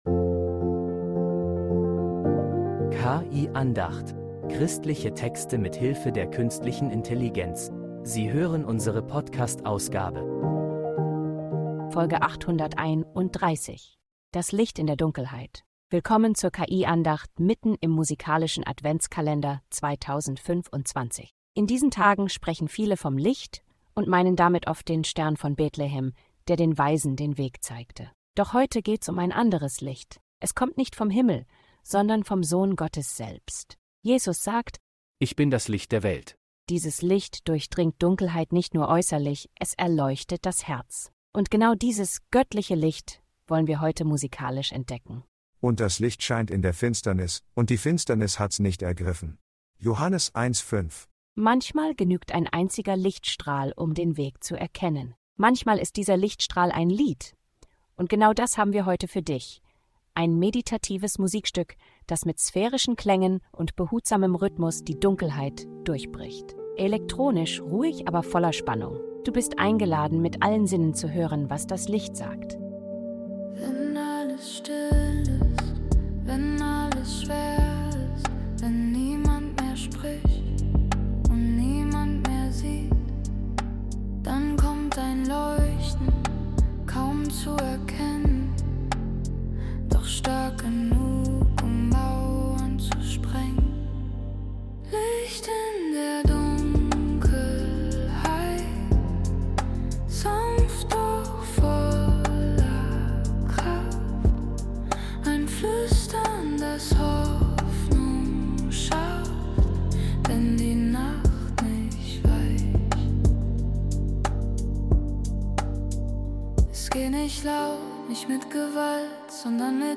Folge bringt ein sphärisches Lied Hoffnung ins Herz.